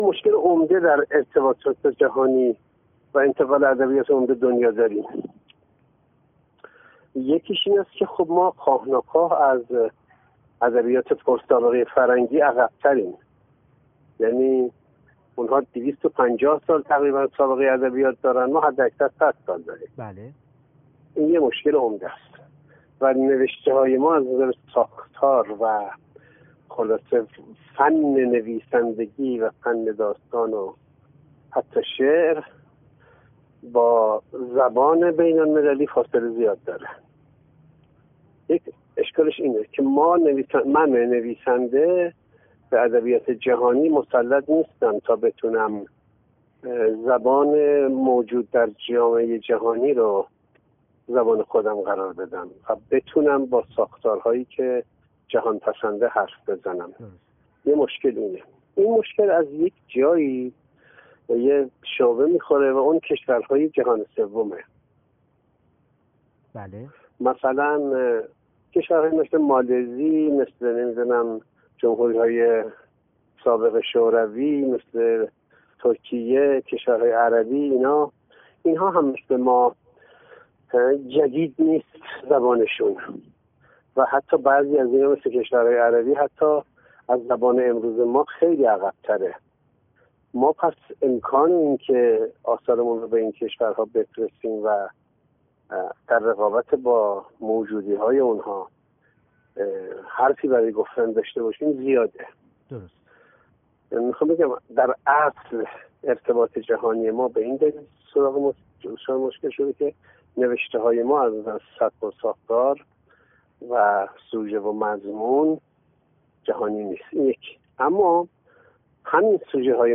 مصطفی رحماندوست در گفت‌وگو با ایکنا: